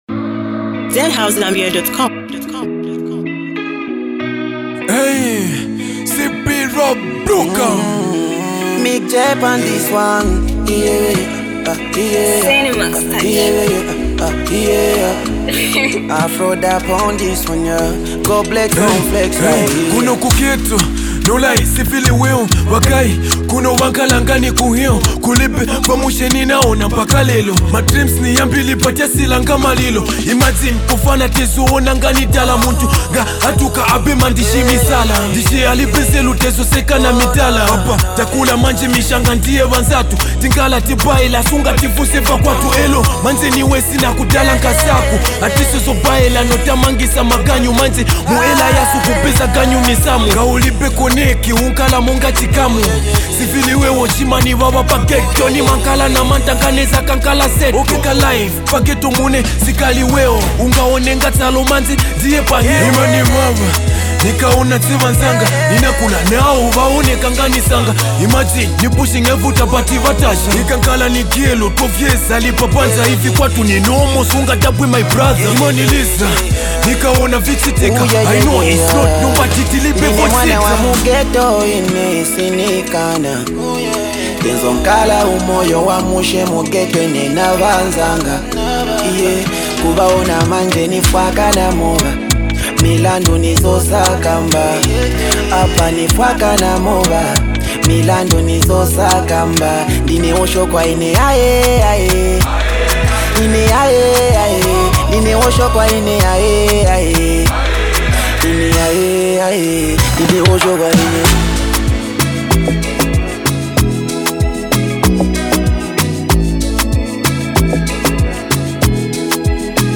is a hard-hitting hip-hop anthem